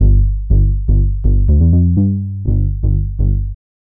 House Bass_123_G.wav